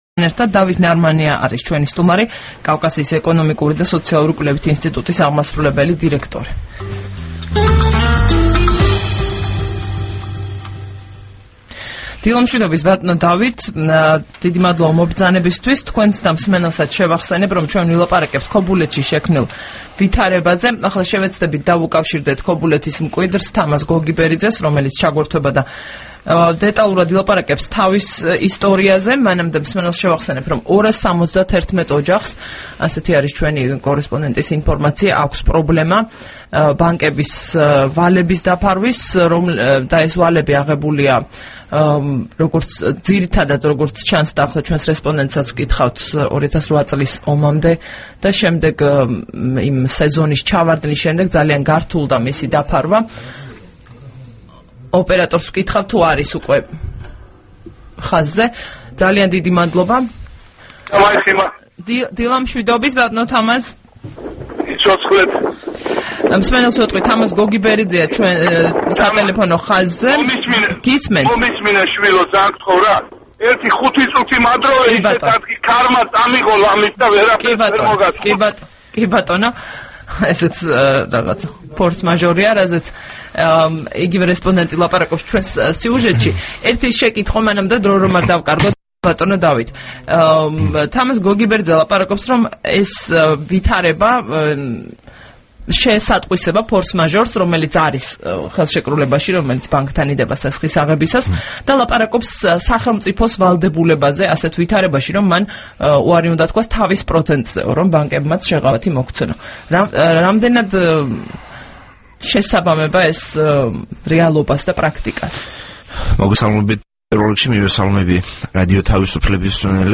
2 აპრილს რადიო თავისუფლების დილის გადაცემის სტუმარი იყო დავით ნარმანია, CIESR-ის აღმასრულებელი დირექტორი.
საუბარი დავით ნარმანიასთან